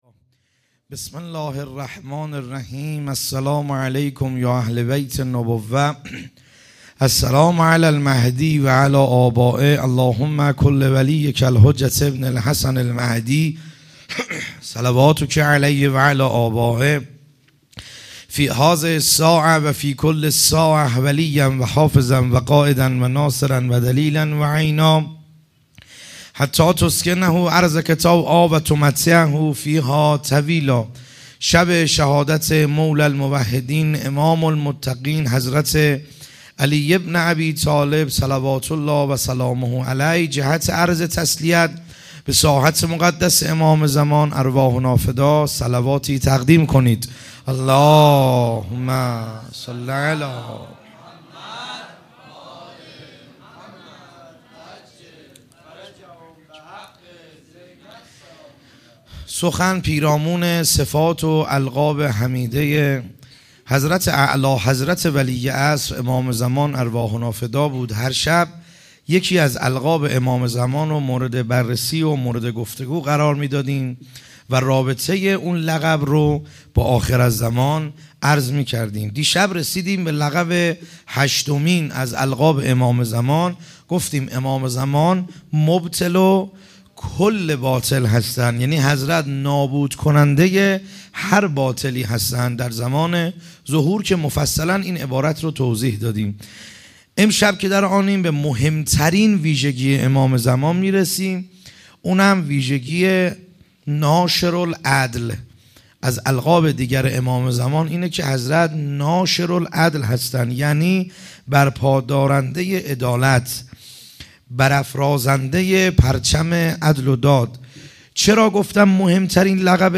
خیمه گاه - بیرق معظم محبین حضرت صاحب الزمان(عج) - سخنرانی ا شب نهم